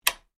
Щелчок выключателя звук